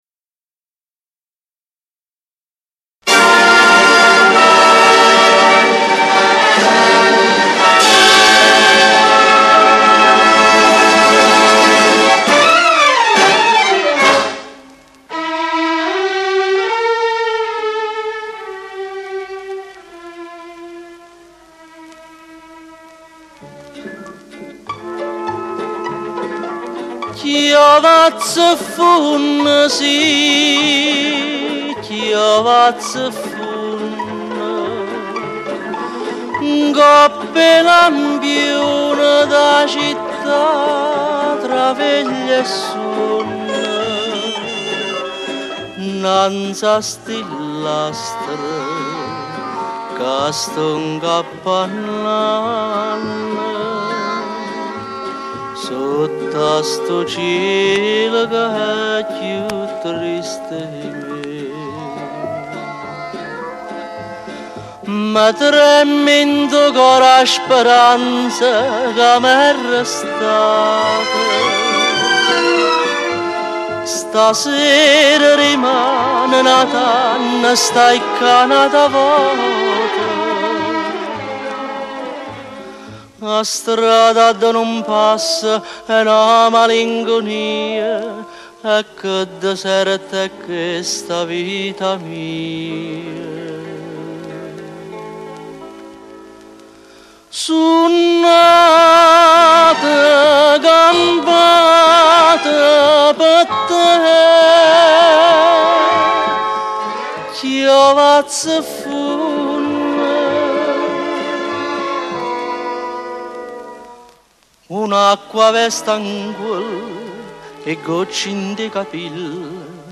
con Orchestra